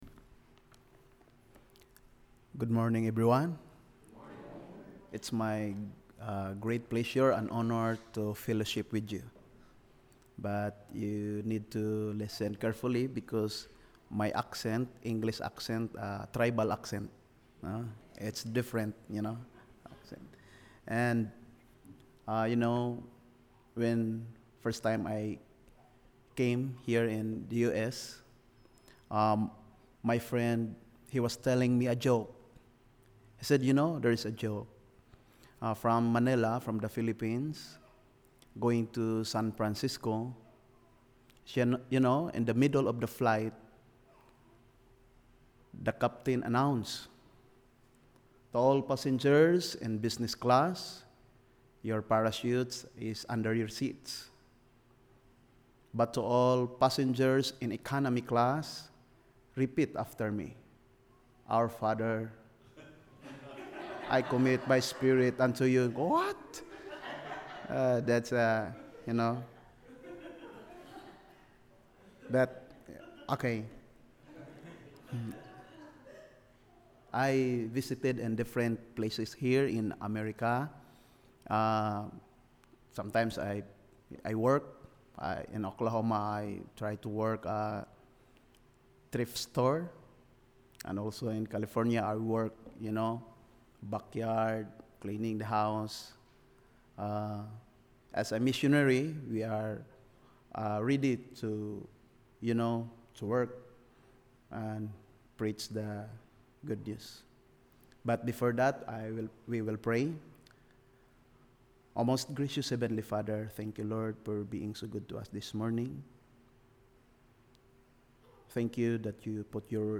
God’s Calling to Action – August 13 Sermon
CedarForkSermon-8-13-17.mp3